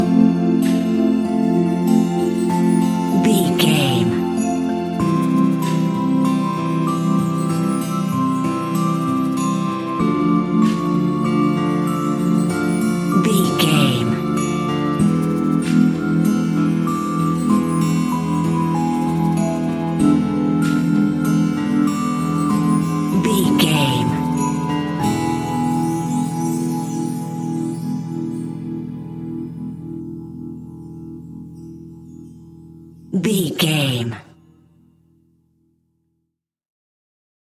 Aeolian/Minor
childrens music
fun
childlike
cute
happy
kids piano